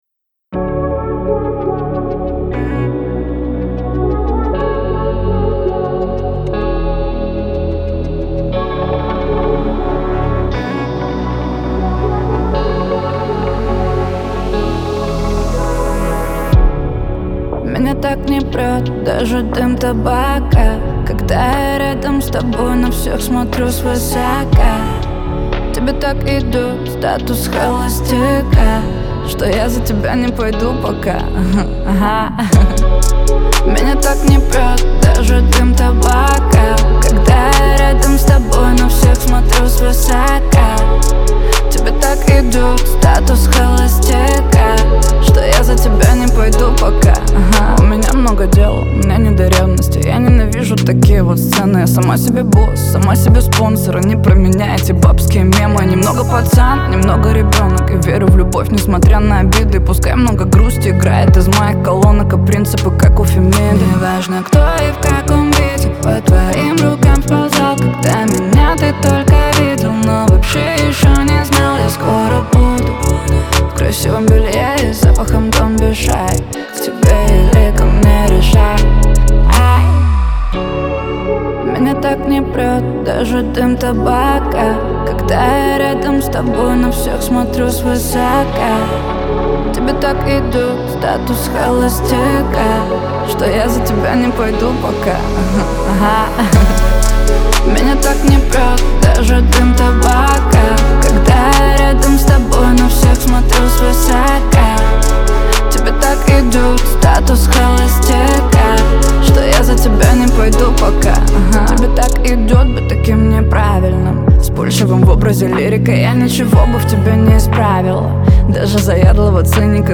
это яркий поп-трек с элементами R&B